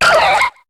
Cri de Sancoki dans Pokémon HOME.